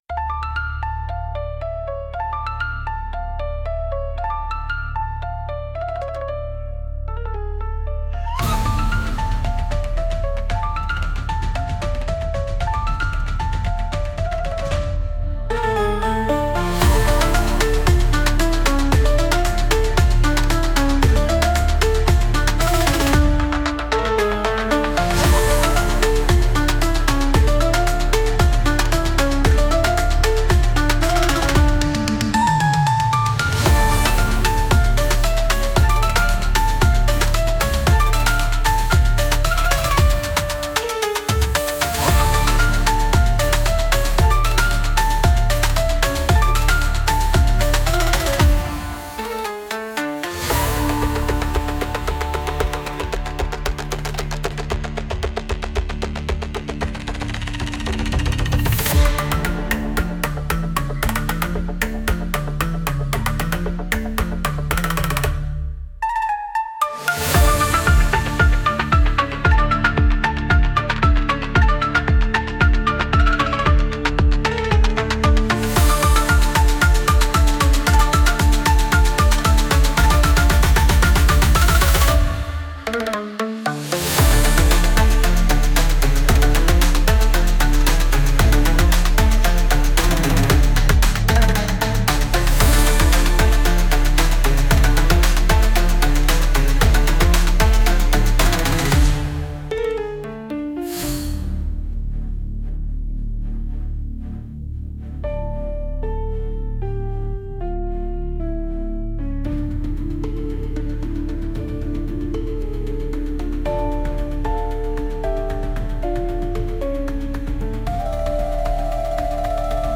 🤖🌍🔮 Electronic and ethnic music
無機質で機械的なエレクトロニック・サウンドと、熱気あふれるエキゾチックな民族音楽。
近未来的でありながら、どこか古代の儀式のようなミステリアスな雰囲気が漂います。